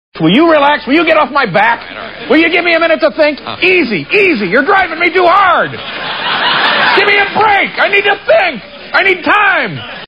Category: Television   Right: Personal
Tags: David Letterman Letterman David Letterman clips tv talk show great audio clips